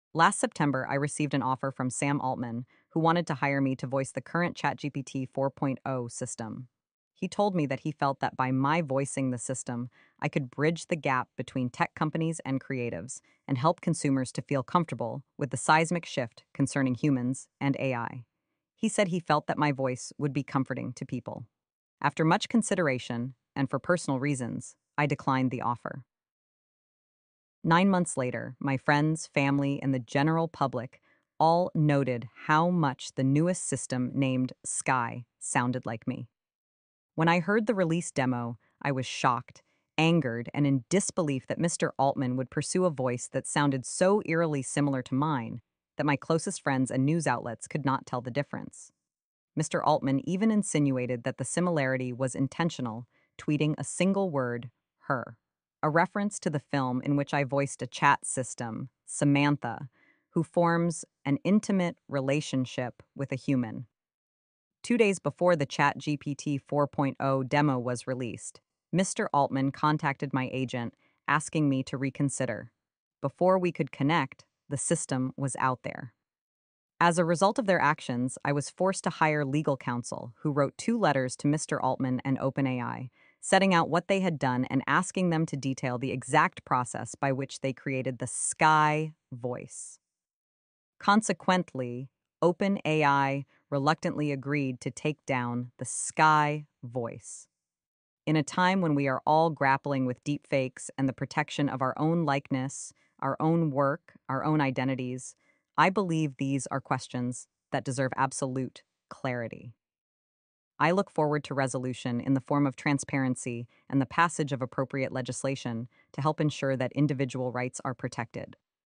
Text-to-Speech
data: most accurate sky voice sample ffmpeg denoised